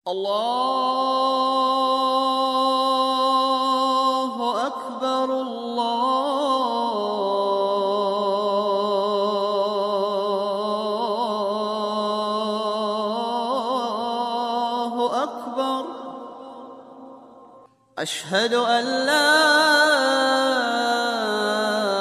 На этой странице собраны записи Азана — проникновенного исламского призыва к молитве.
Звук призыва к молитве Азана